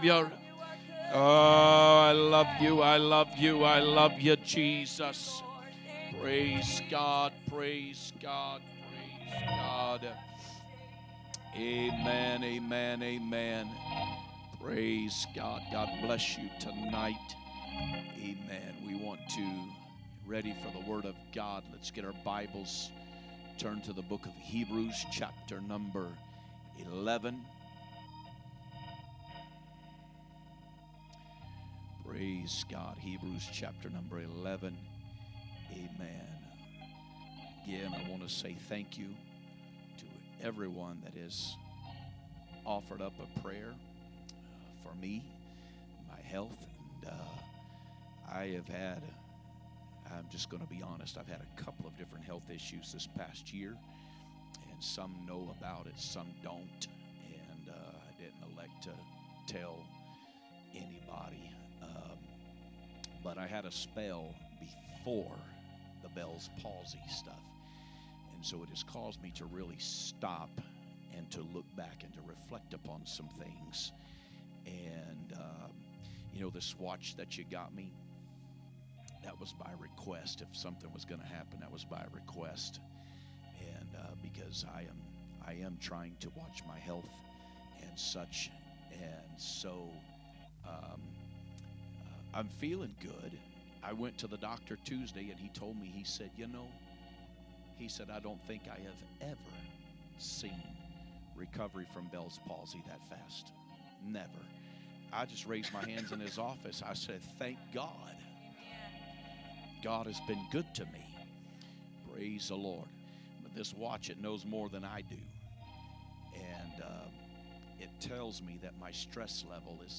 Wednesday Service